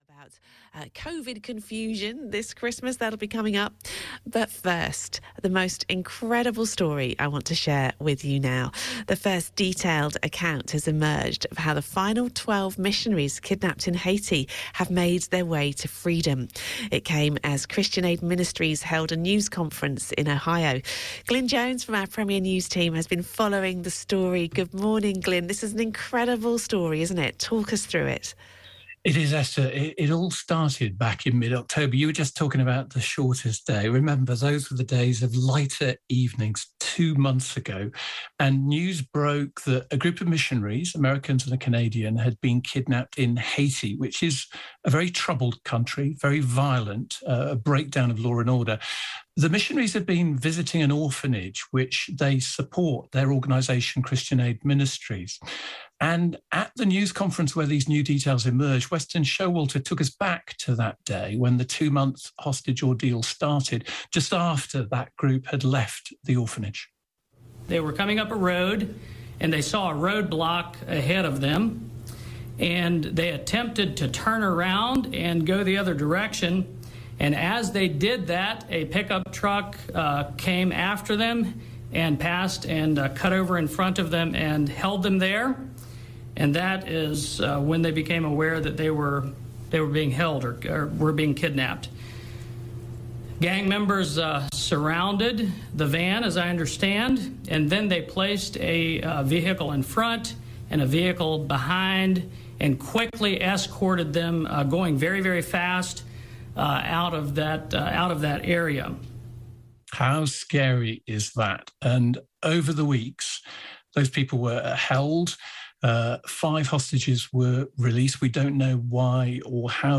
Haiti Missionaries – live voiced package with audio from Christian Aid Ministries’ news conference about the release/escape of missionaries kidnapped in Haiti (December 2021)